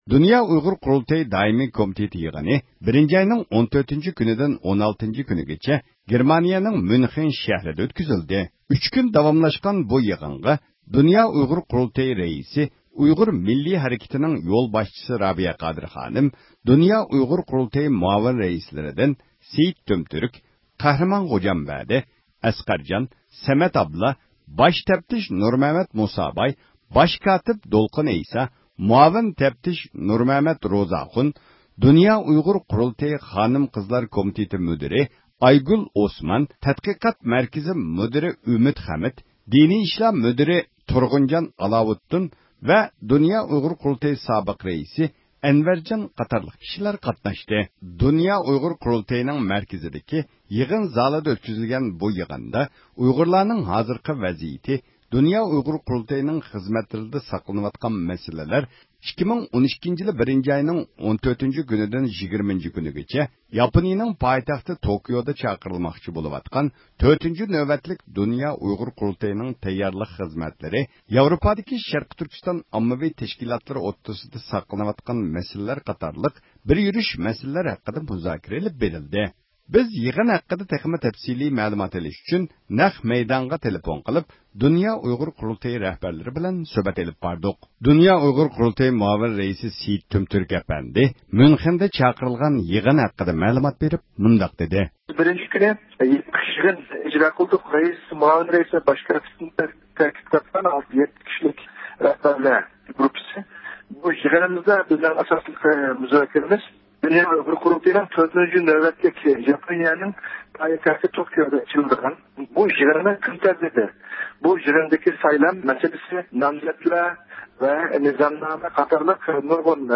بىز يىغىن ھەققىدە تېخىمۇ تەپسىلىي مەلۇمات ئېلىش ئۈچۈن نەق مەيدانغا تېلېفون قىلىپ د ئۇ ق رەھبەرلىرى بىلەن سۆھبەت ئېلىپ باردۇق.